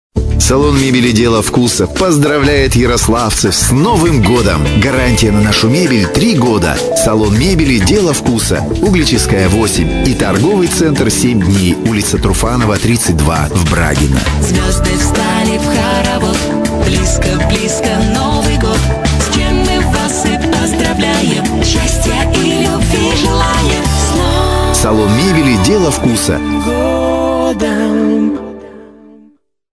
Рекламные радио ролики записаны в формате mp3 (64 Kbps/FM Radio Quality Audio).
(Голос "Деда Мороза"). 30 сек. 232 кбайт.